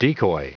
Prononciation du mot decoy en anglais (fichier audio)
Prononciation du mot : decoy